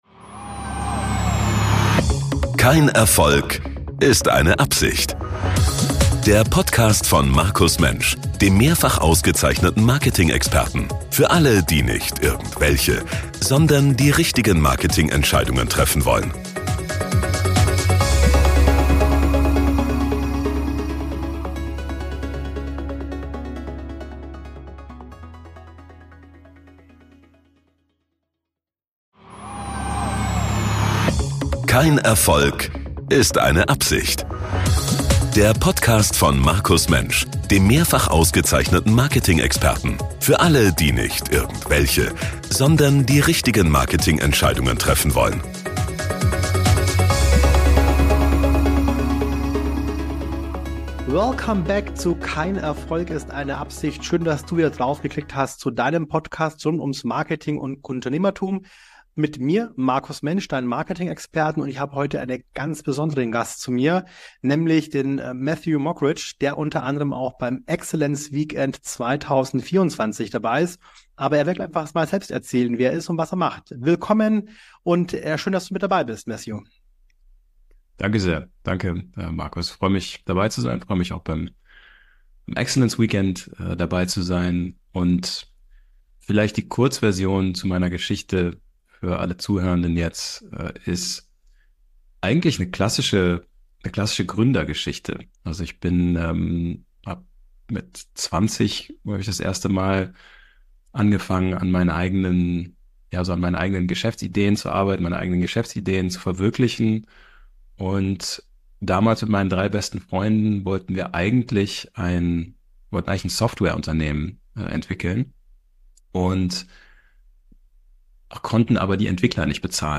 im Interview ~ Kein Erfolg ist eine Absicht Podcast